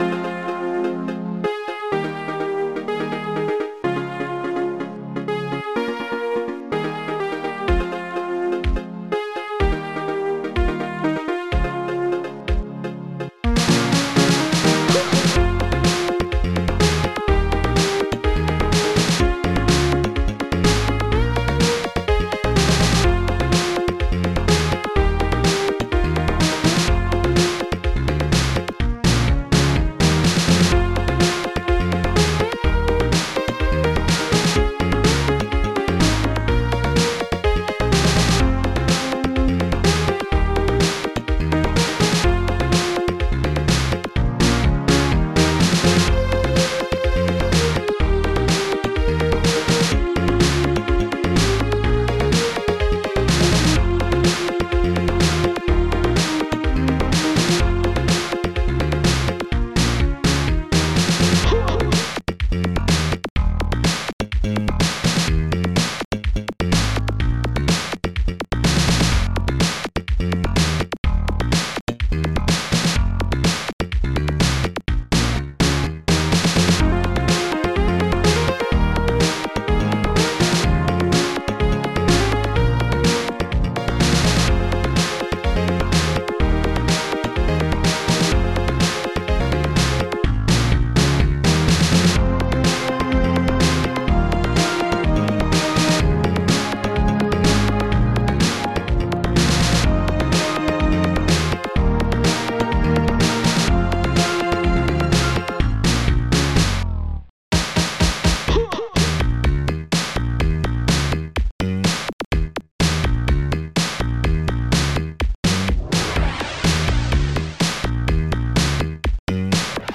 st-66:e-chord2 st-66:e-chord1 st-66:lead12 st-66:440thick-bk st-66:bass4 st-66:snare14 st-66:laserdrum st-66:strings2 st-67:hoah st-05:jmj4 st-67:escape4